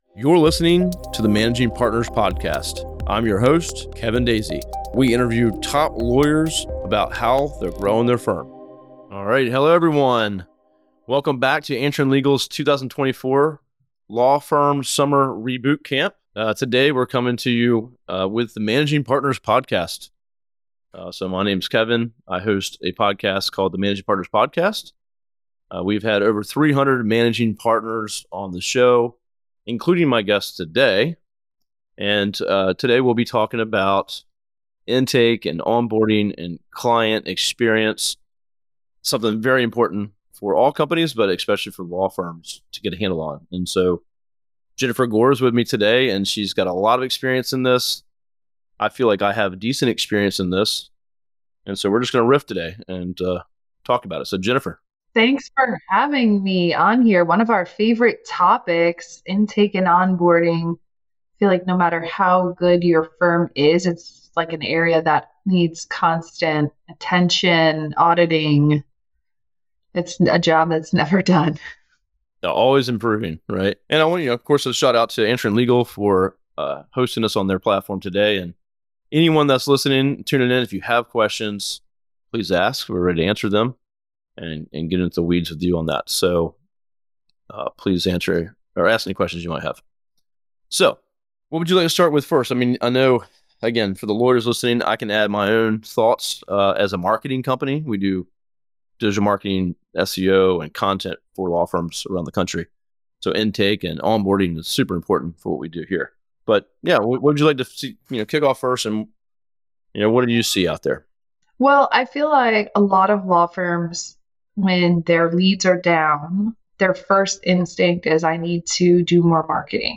On today’s episode, we take you back to Answering Legal’s 2024 Law Firm Summer Reboot Camp